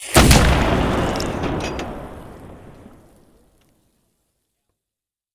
Heavy Weapons And Explosions Sound Effects – Cannon – Free Music Download For Creators
Heavy_Weapons_And_Explosions_Sound_Effects_-_cannon.mp3